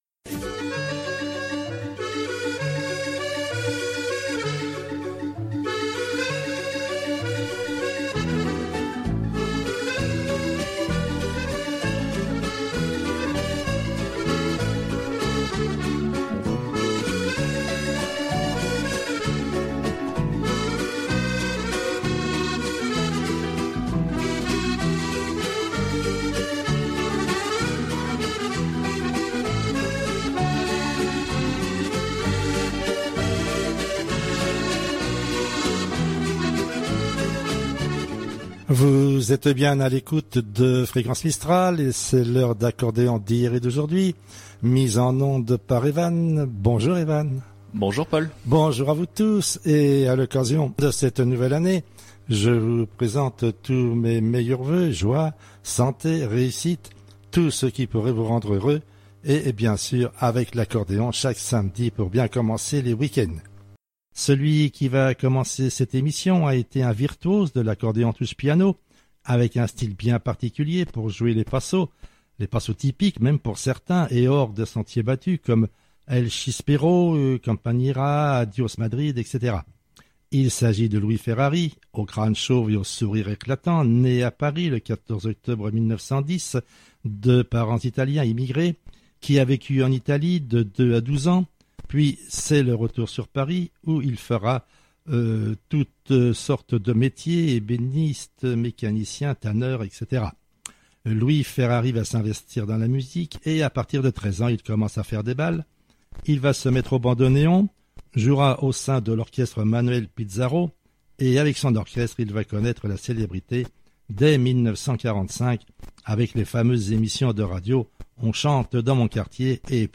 Amateurs d’accordéon